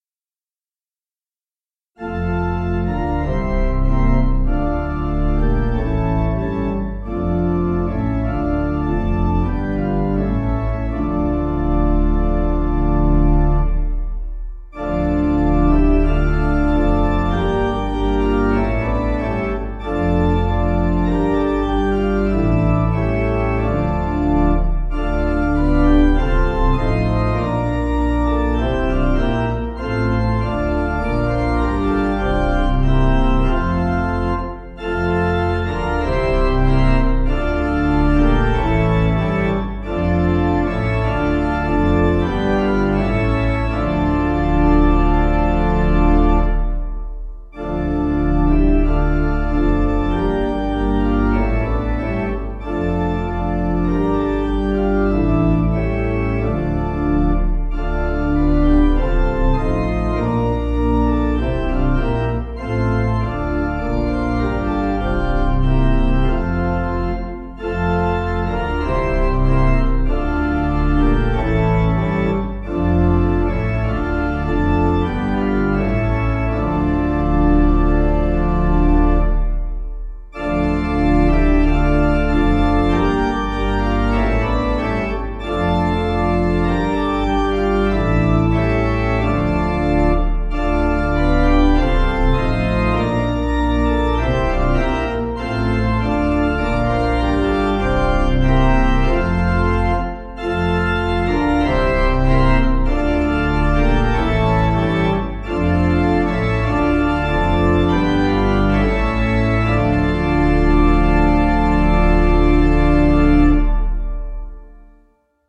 Organ
(CM)   3/Dm 481.8kb